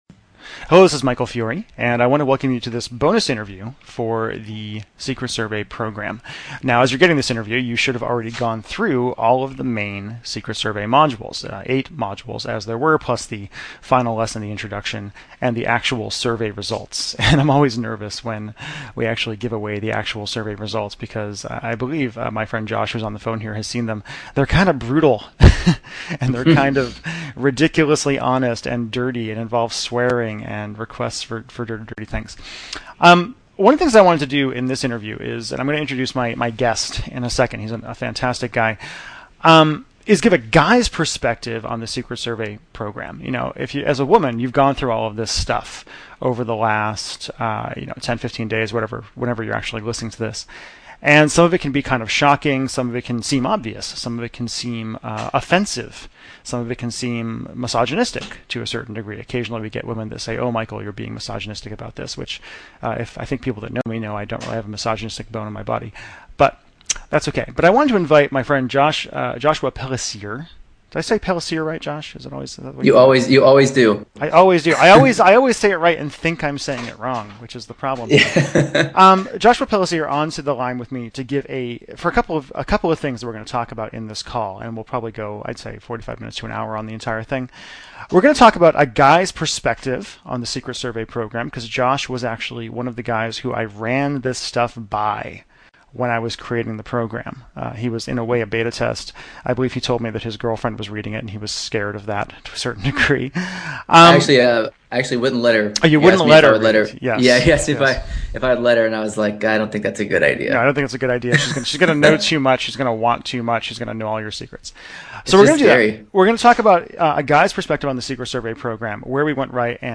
Human Lie Detector: An Interview